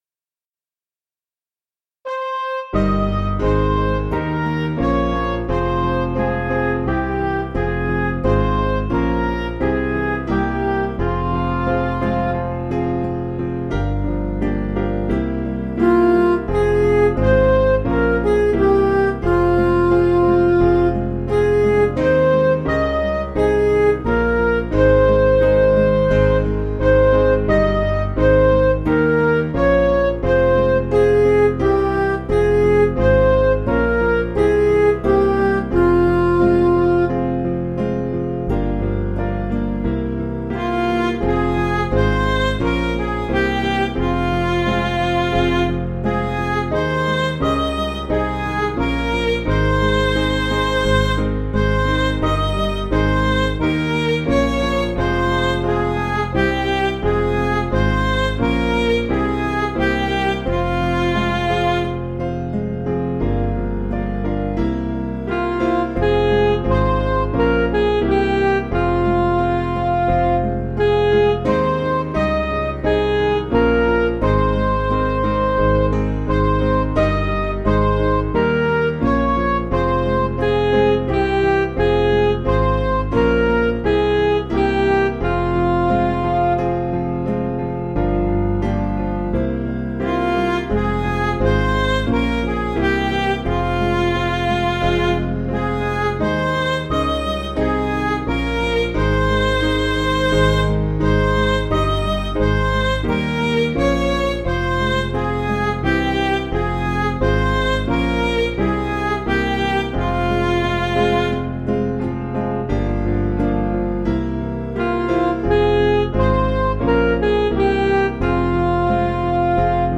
Piano & Instrumental
(CM)   6/Fm